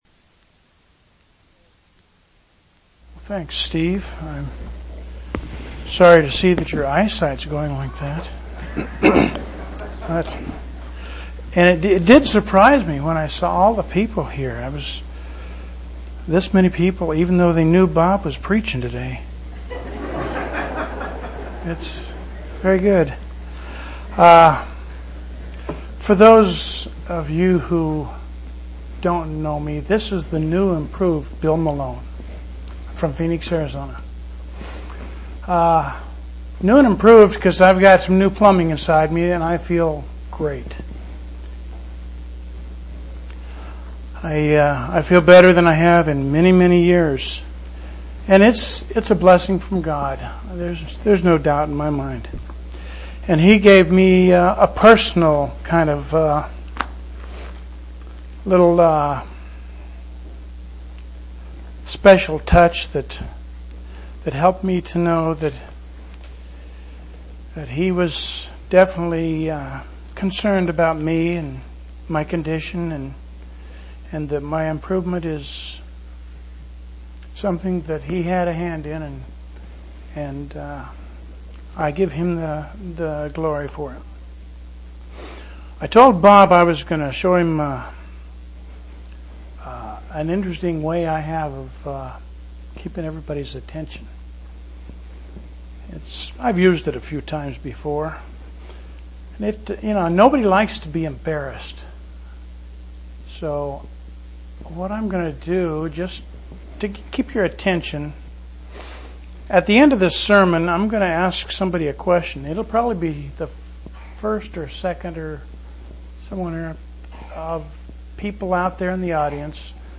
11/14/1999 Location: East Independence Local Event